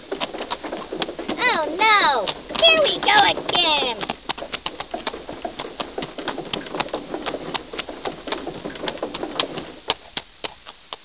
00895_Sound_Windmill.mp3